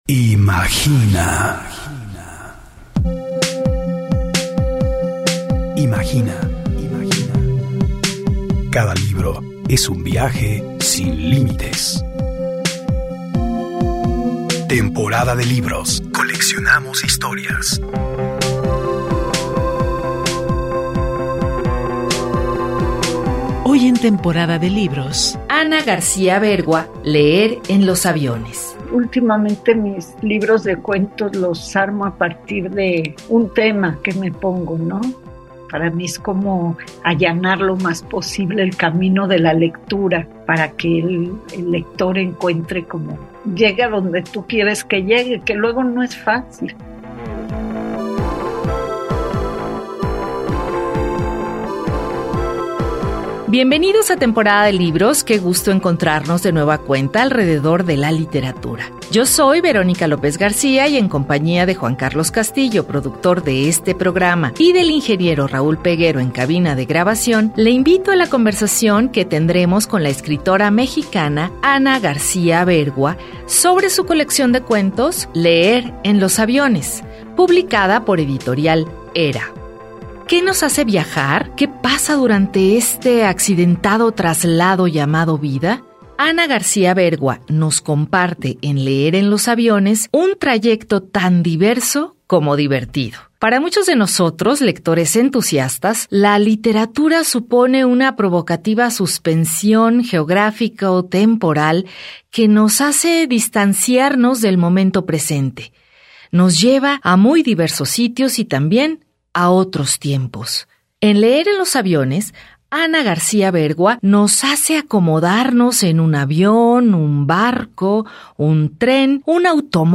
Sintoniza ahora en el 104.3 FM